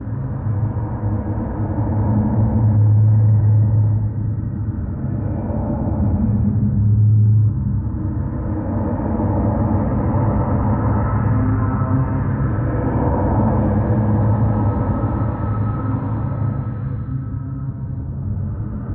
Index of /sound/ambient/atmosphere/
corridor.mp3